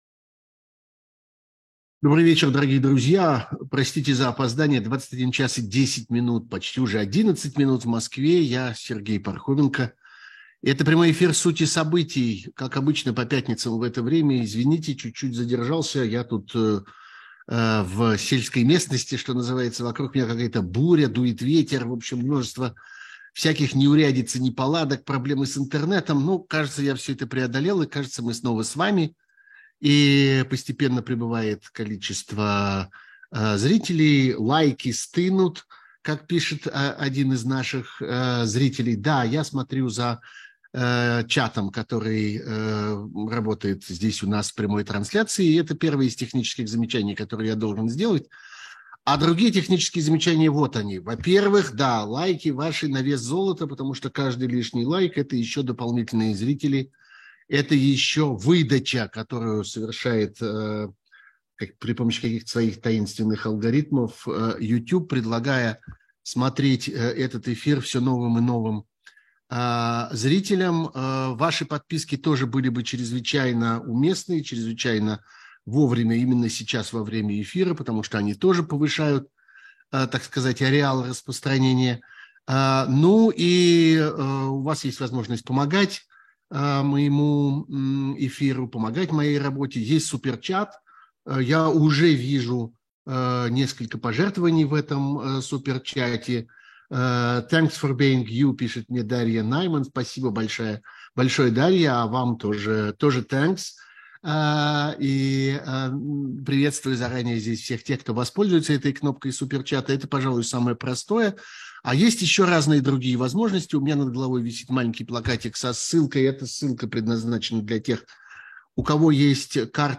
Это прямой эфир «Сути событий», как обычно по пятницам в это время.
Вокруг меня какая-то буря, дует ветер.